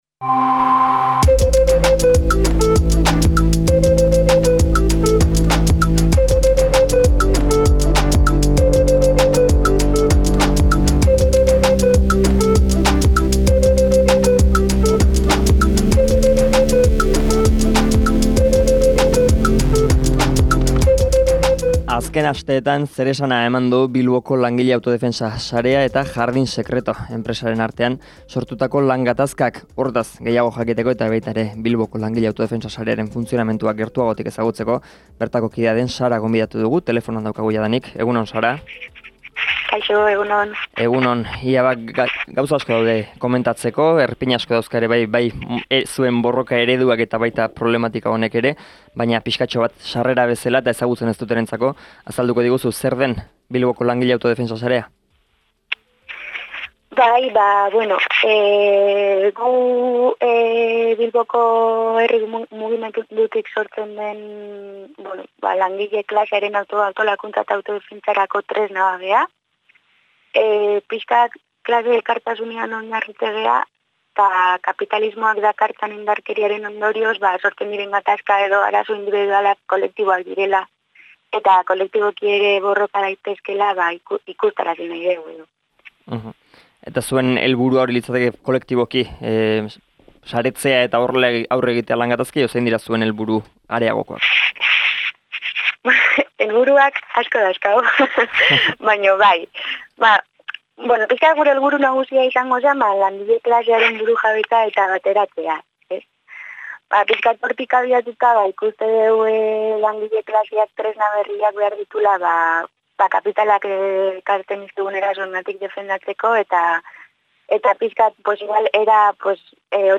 Elkarrizketa osoa hemen entzungai.